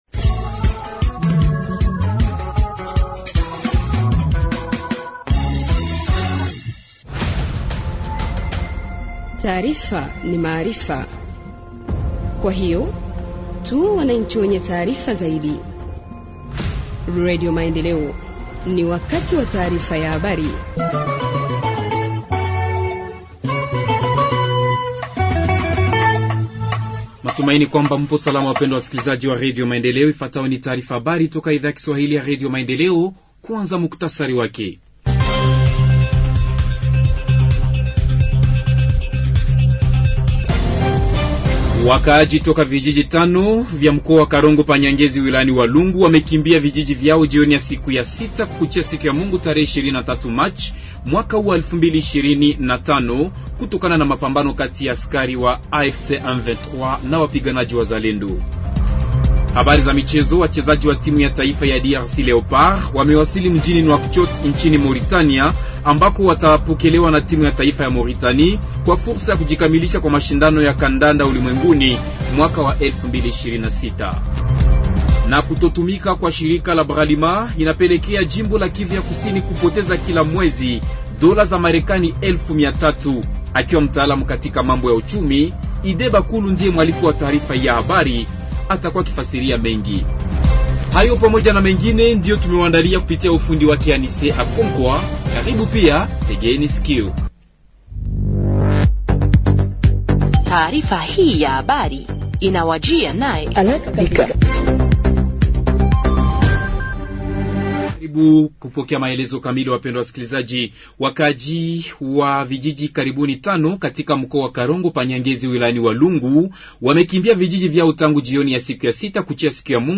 Journal en swahili du 24 mars 2025 – Radio Maendeleo